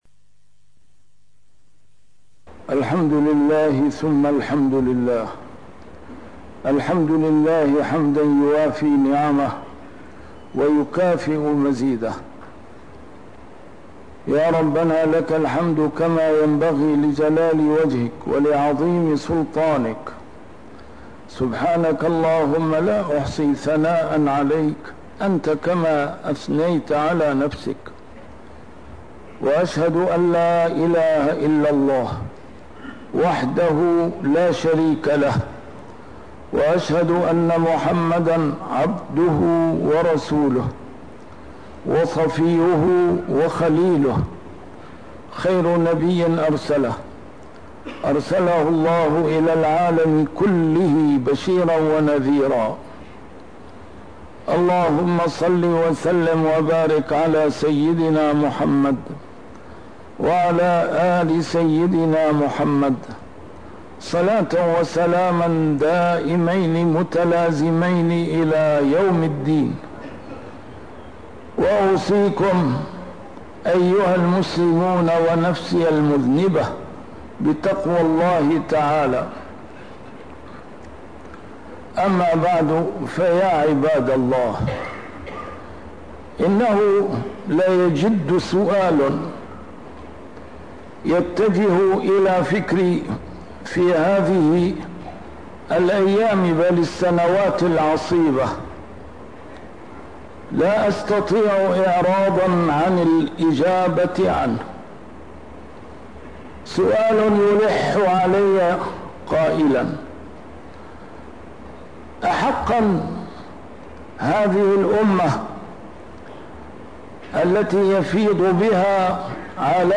A MARTYR SCHOLAR: IMAM MUHAMMAD SAEED RAMADAN AL-BOUTI - الخطب - موقف العرب تجاه محن إخوانهم